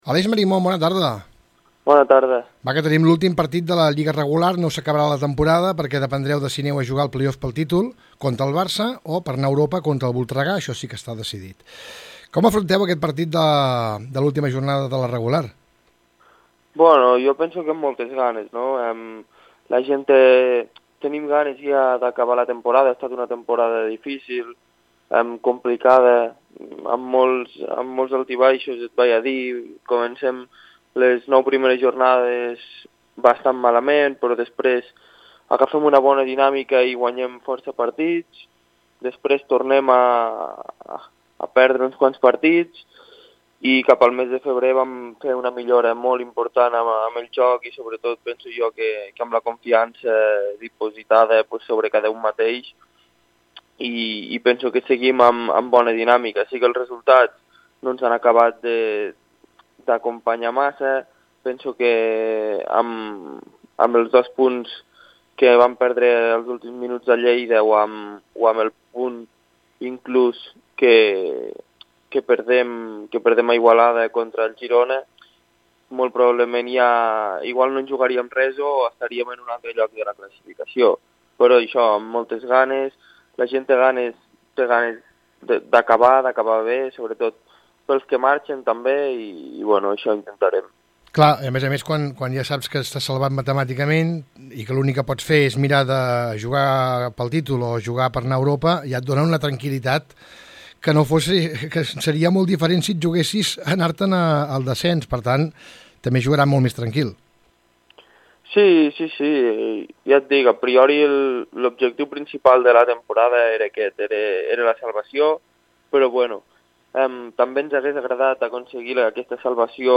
Aquí podeu escoltar l’entrevista amb el jugador de l’Igualada